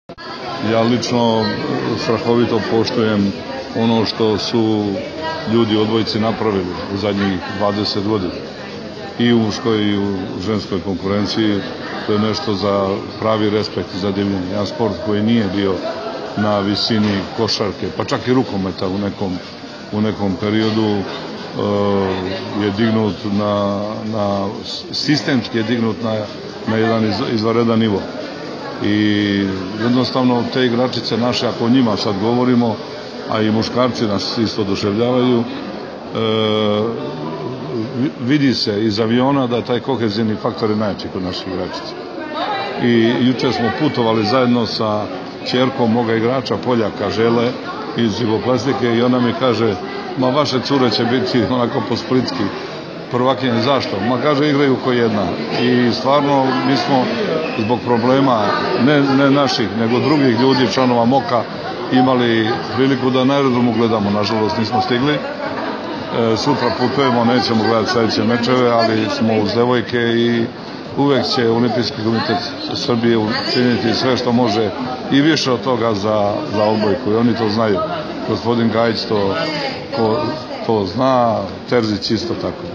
IZJAVA BOŽIDARA MALJKOVIĆA